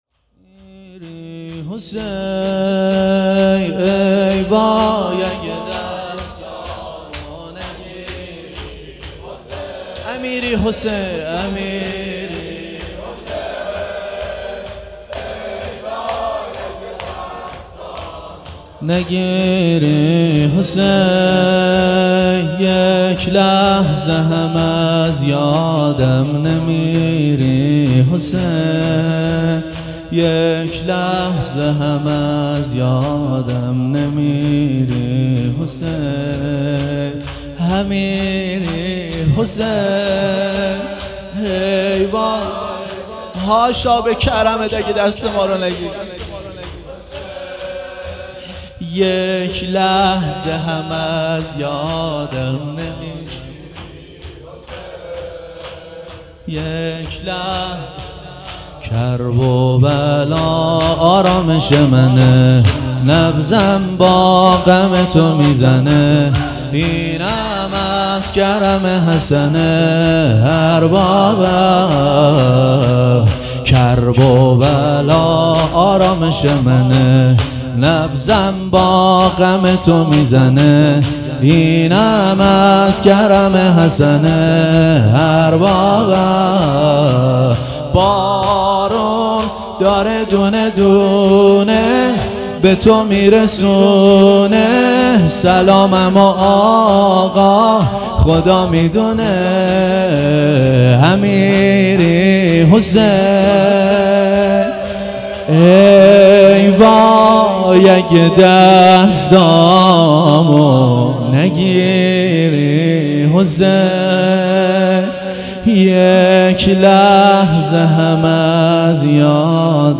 امیری حسین ای وای اگر دستمو نگیری _ شور
اقامه عزای شهادت ام البنین سلام الله علیها